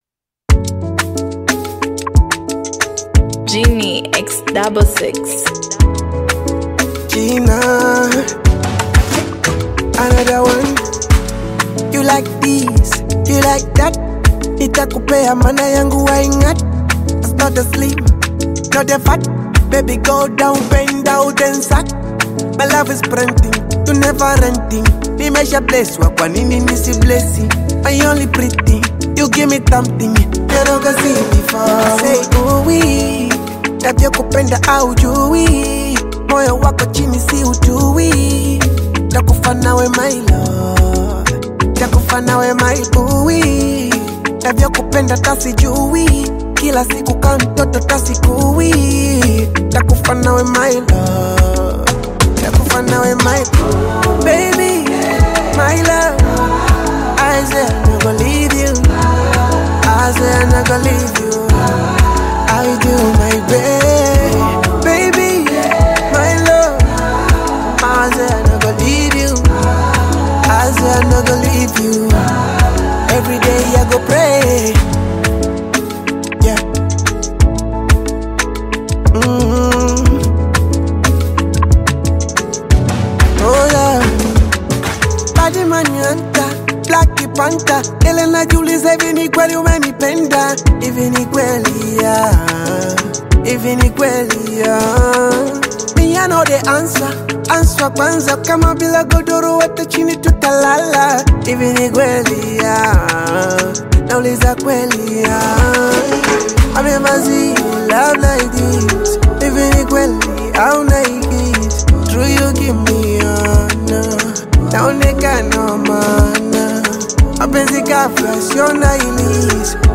emotive Afro-fusion single
With expressive vocal delivery and polished production
Genre: Bongo Flava